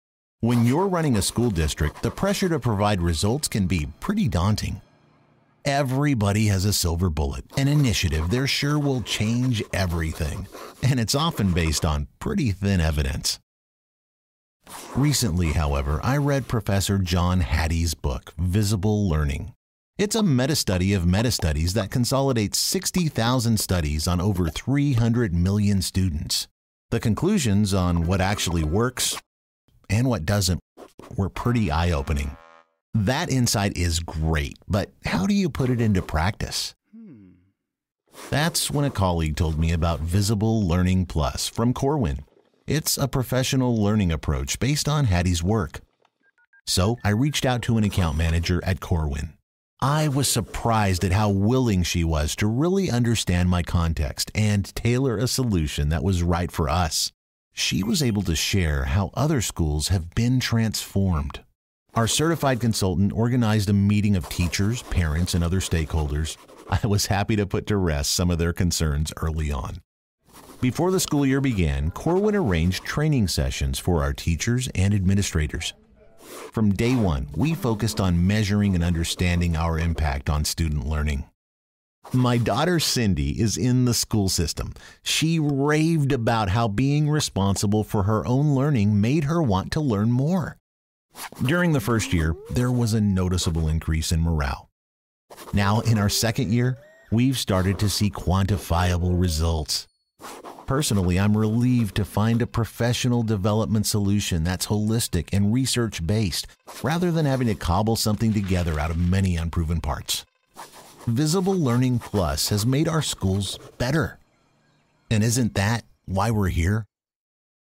Male
English (North American)
Explainer Videos
Explainer Demo
Words that describe my voice are Real, Fun, Friendly.